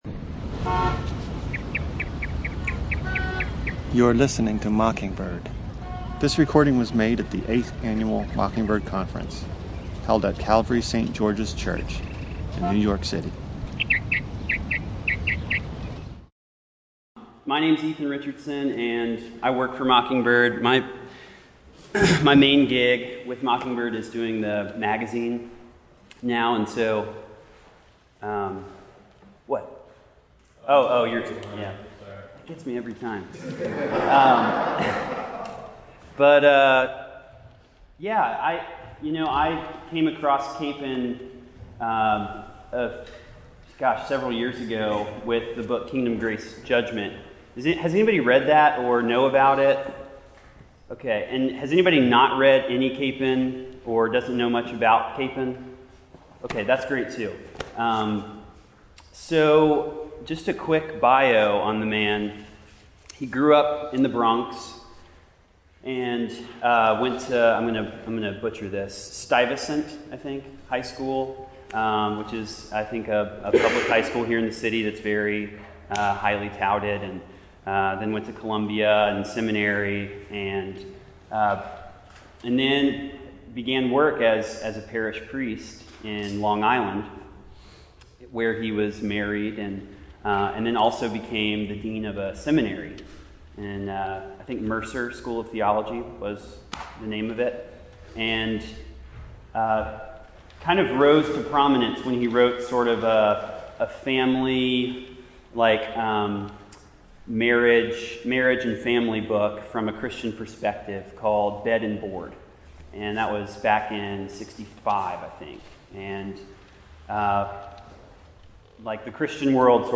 Venue: 2015 NYC Mockingbird Conference